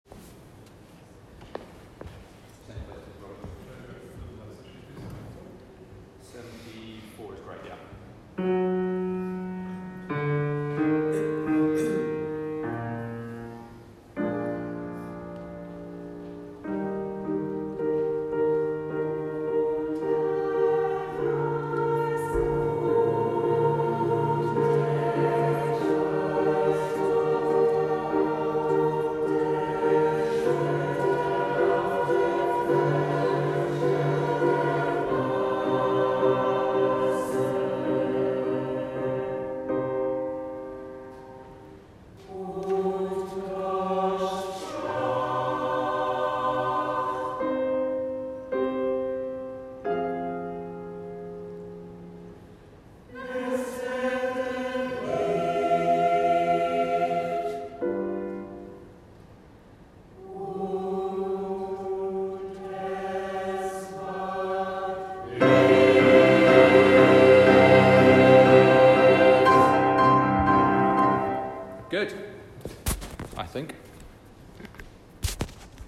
Recognise that the piano is rather loud and recording on a phone is very unforgiving, and makes us sound weedier than we are in real life, so don’t be too critical.